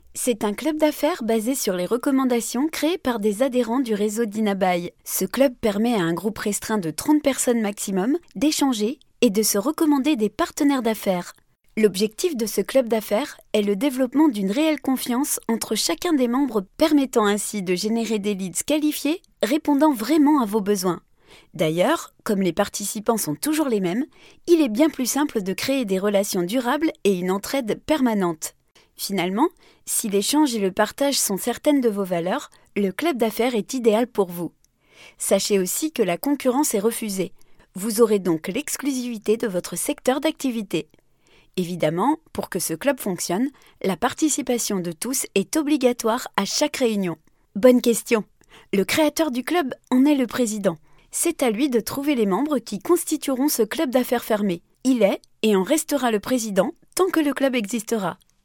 French female voice over talent professional ...